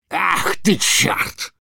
Звук подрывника с фразой Ах ты черт